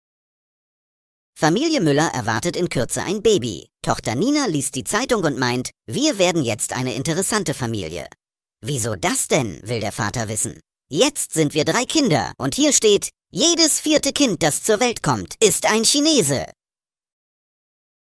Vorgetragen von unseren attraktiven SchauspielerInnen.